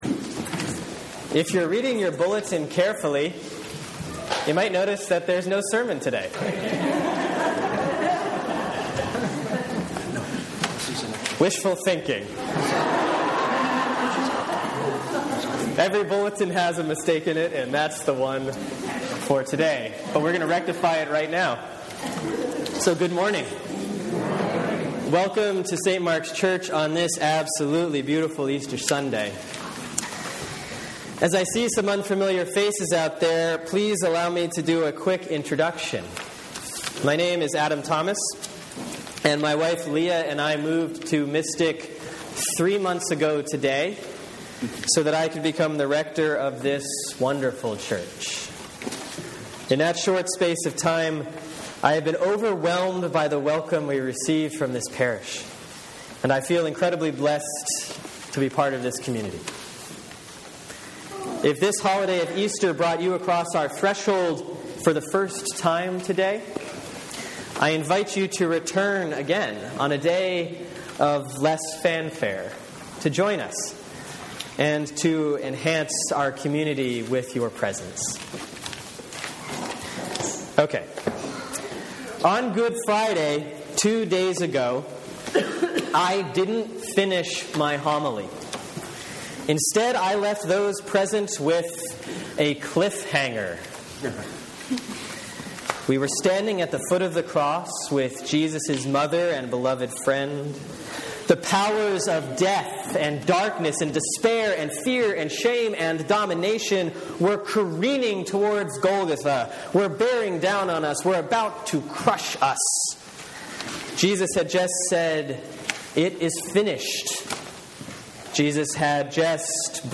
Sermon for Easter Sunday, Year A || April 20, 2014 || Matthew 28:1-11